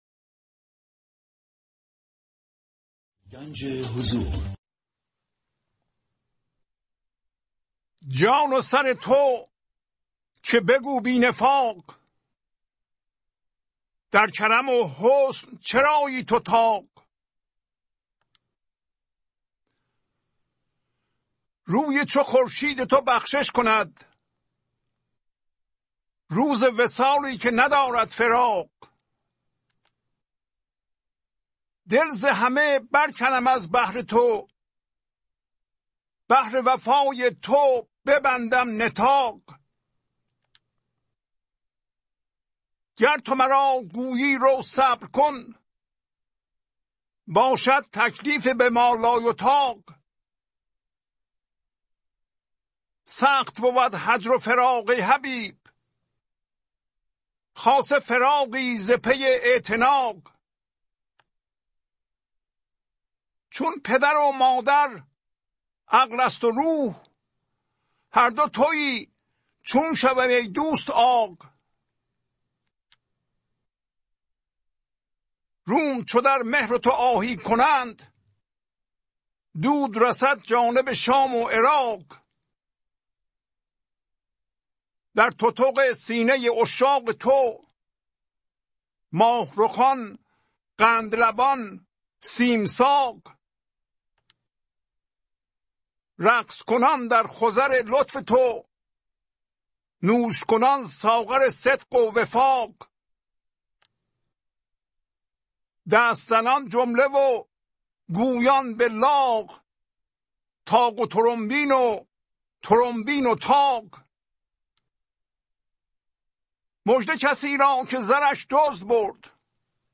914-Poems-Voice.mp3